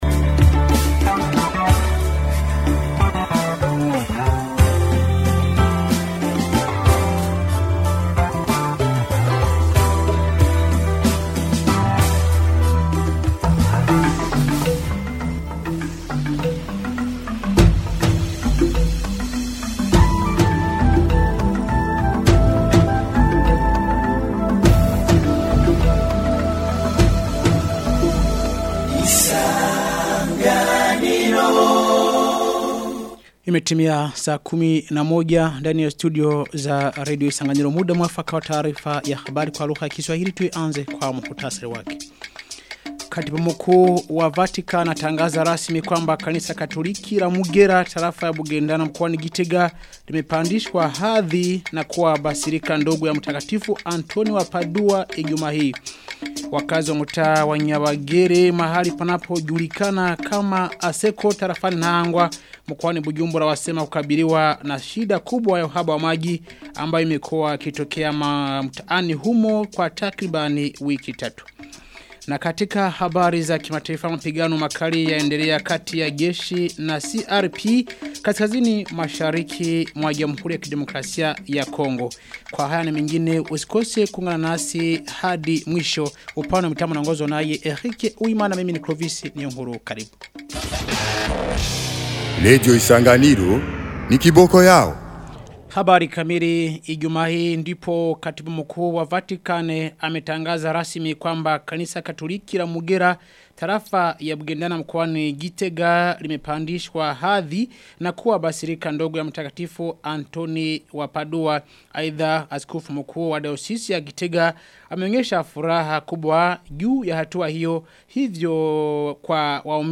Taarifa ya habari ya tarehe 15 Agosti 2025